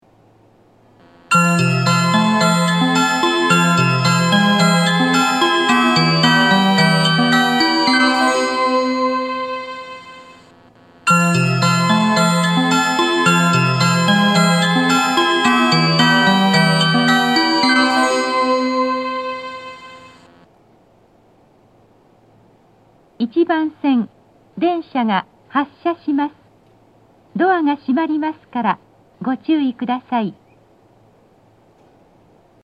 2コーラスです!乗降が少ないので途中切りが大変多いです。